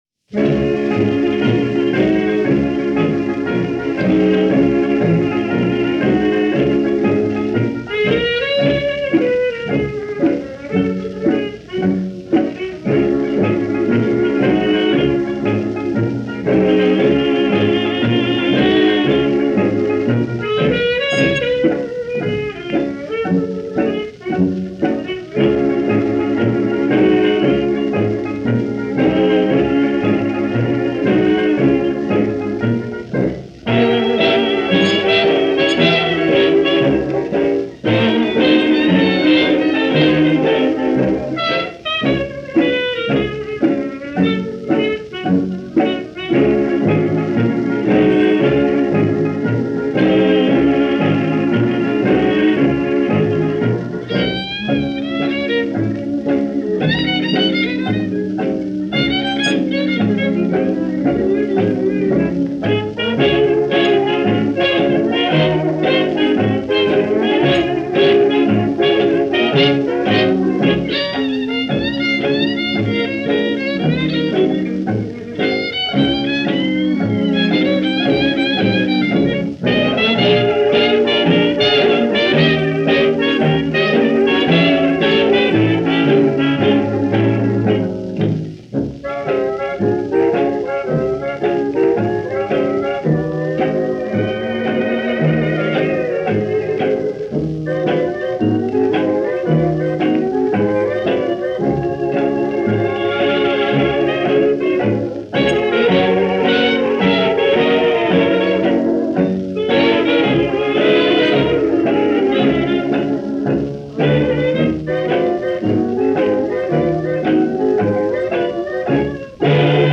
Taking a mini-break and diving into some 20’s Hot Jazz